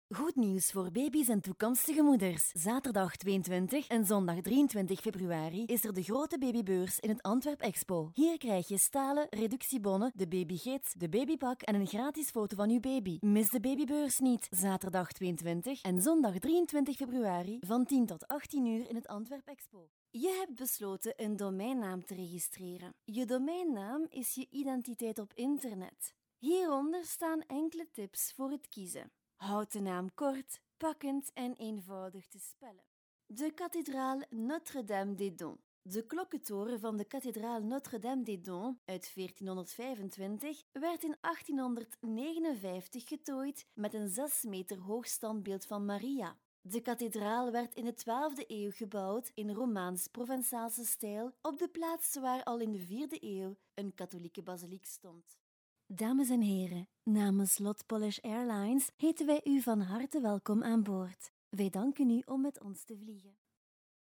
Professionelle Sprecher und Sprecherinnen
Belgisch
Weiblich